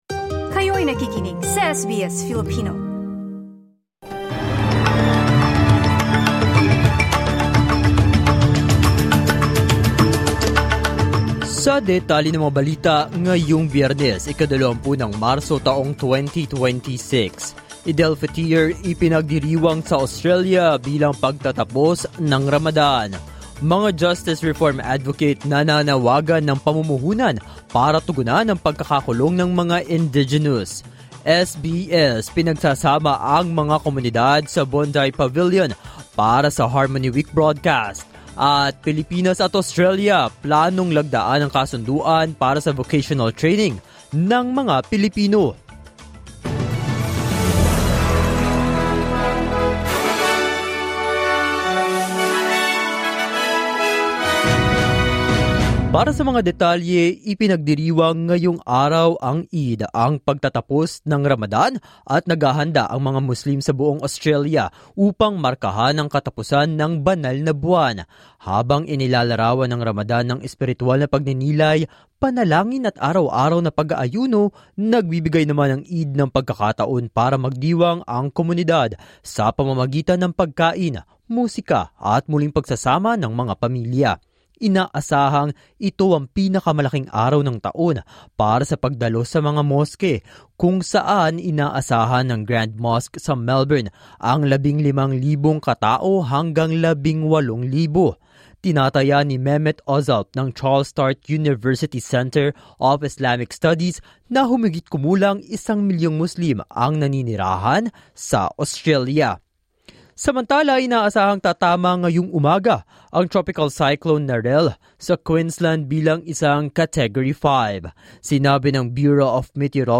SBS News in Filipino, Friday 20 March 2026